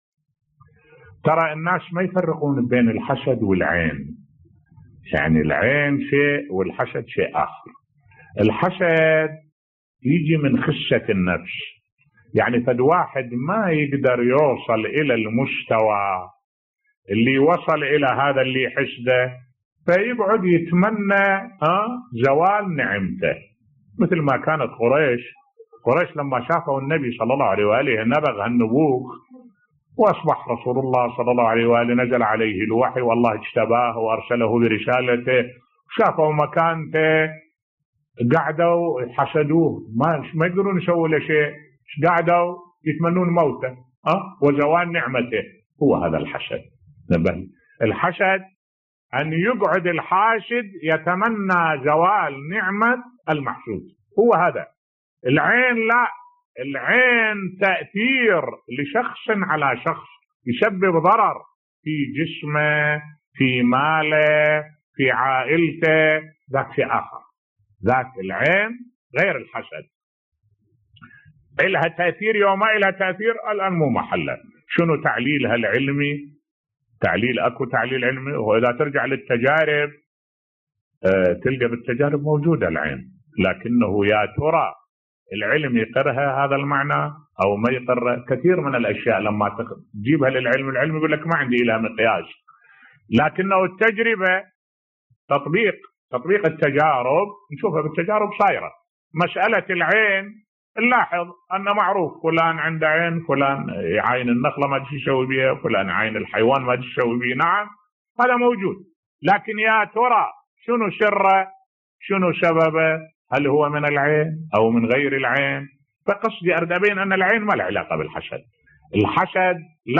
ملف صوتی الفرق بين الحسد و تأثير العين بصوت الشيخ الدكتور أحمد الوائلي